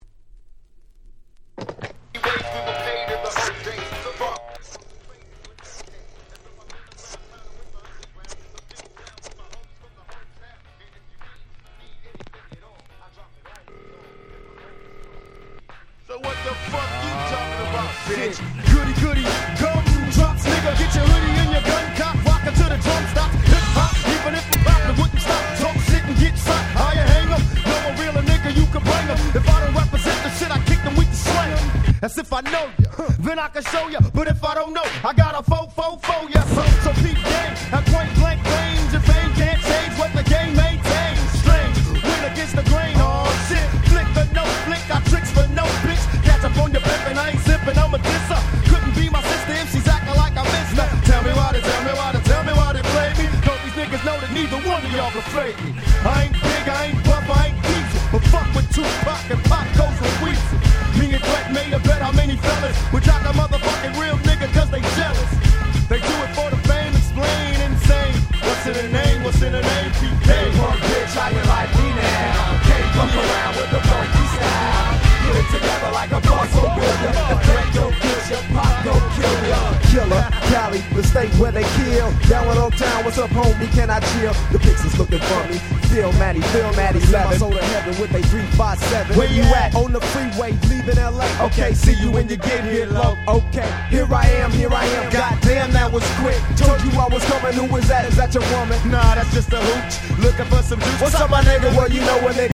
94' Smash Hit Hip Hop !!
90's G-Rap Gangsta Rap